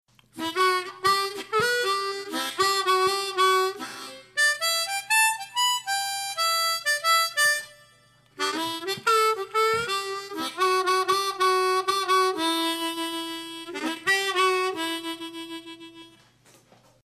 The provided backing track is in Em key and we are going to play a C sharp in 5th position.
Note that in my playing I also incorporated some slaps, pulls and octaves.
Improvisation technique 1 tab for harmonica
TECHNIQUE 5: CALL AND ANSWER. With this technique you play a sentence and then another sentence that seems to be an answer to the previous one.